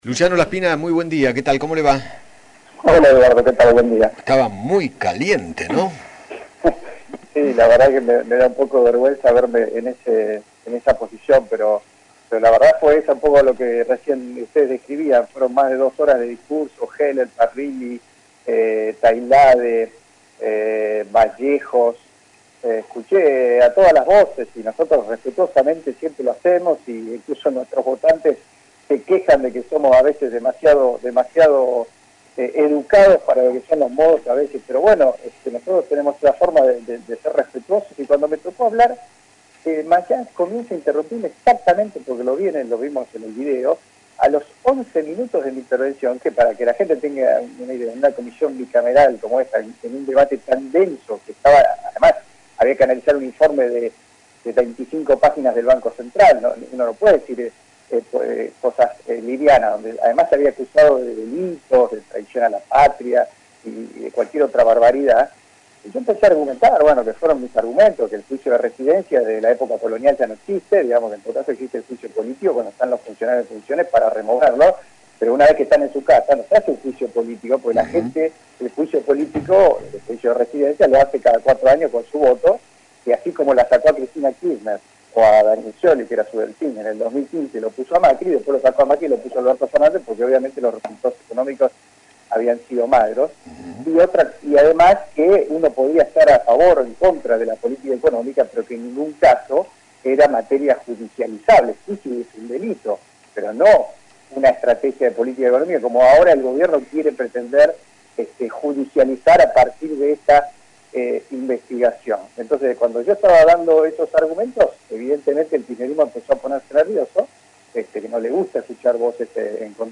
Luciano Laspina, diputado Nacional por Juntos por el Cambio, dialogó con Eduardo Feinmann sobre el fuerte cruce con el senador José Mayans durante la reunión de la Comisión Bicameral de Deuda y criticó la política económica del kirchnerismo. Además, realizó una autocrítica sobre la gestión de su partido.